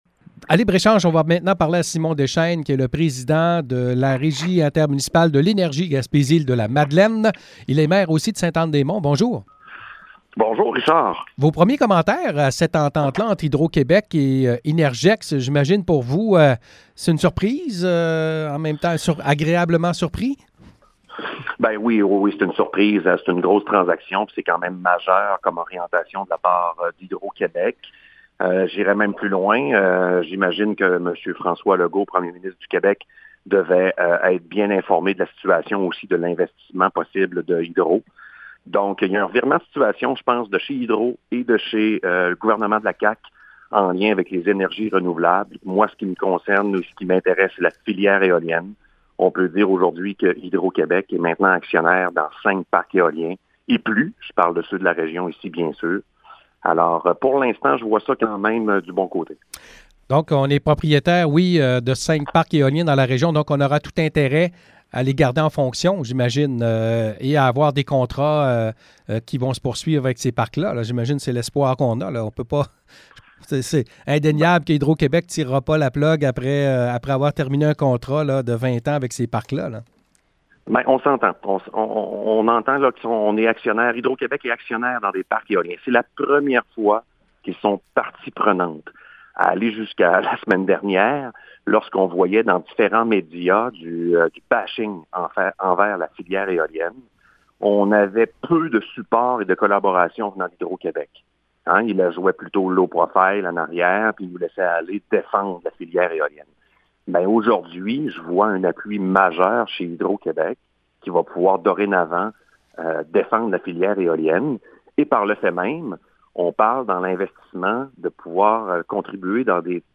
Entrevue avec le président de la régie